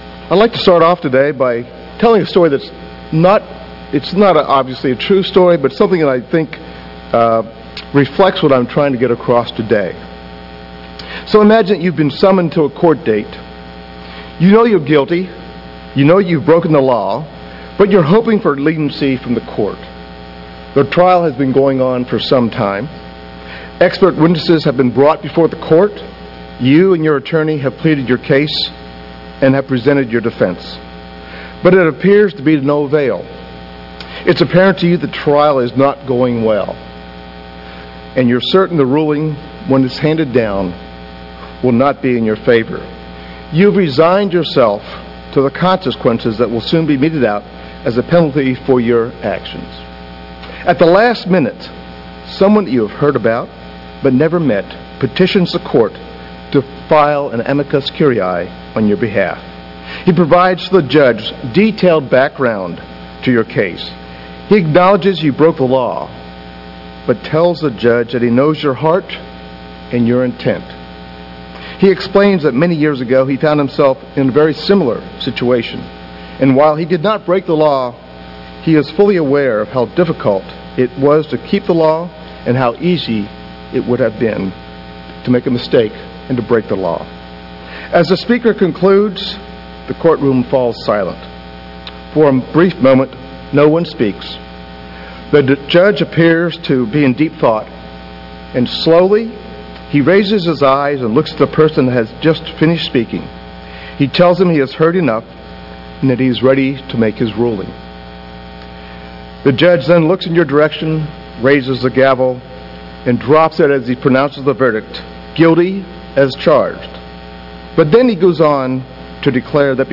Sermons
Given in Atlanta, GA